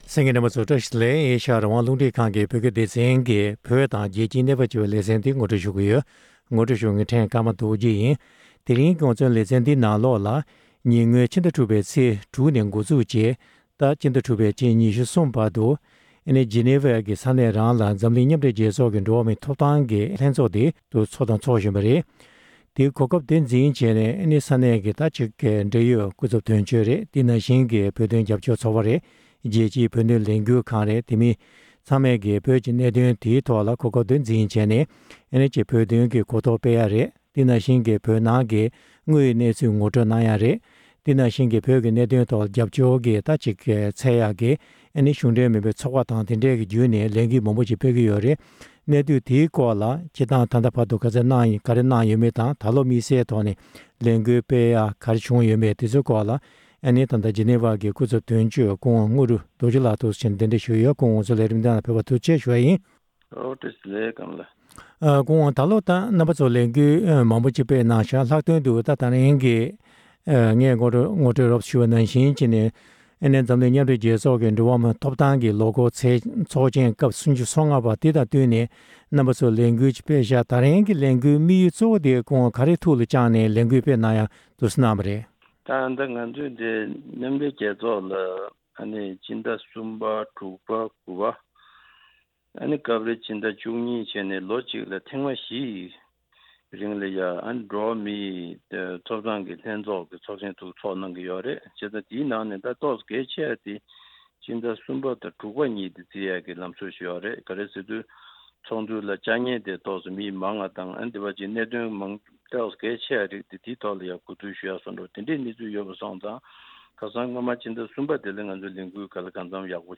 མཉམ་འབྲེལ་རྒྱལ་ཚོགས་ཀྱི་འགྲོ་བ་མིའི་ཐོབ་ཐང་ལྷན་ཁང་གི་ཚོགས་དུས་༣༥པའི་སྐབས་བོད་ཀྱི་གནད་དོན་སྐོར་གོ་རྟོགས་དྲིལ་བསྒྲགས་གནང་བའི་ཐད་བགྲོ་གླེང༌།